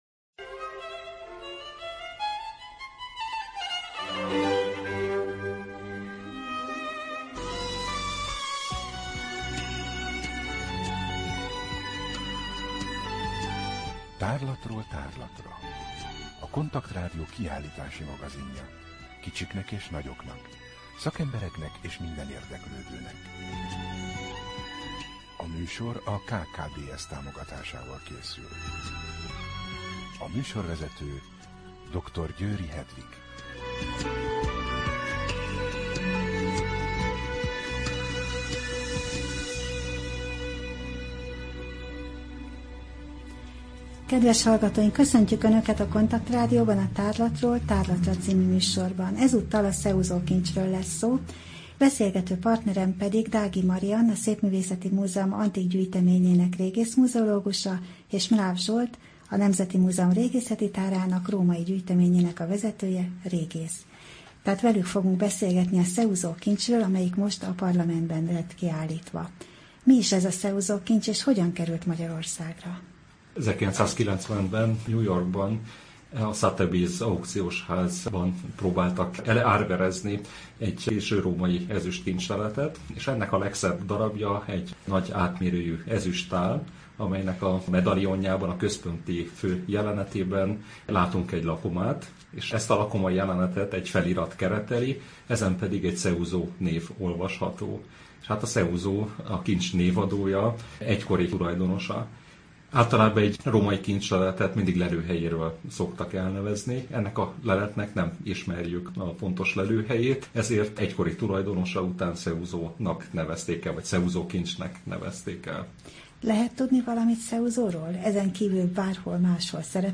Rádió: Tárlatról tárlatra Adás dátuma: 2014, June 16 Tárlatról tárlatra / KONTAKT Rádió (87,6 MHz) 2014. június 16. A műsor felépítése: I. Kaleidoszkóp / kiállítási hírek II. Bemutatjuk / Seuso kincs, Parlament A műsor vendége